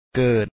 ke3et